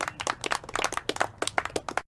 post_sent.ogg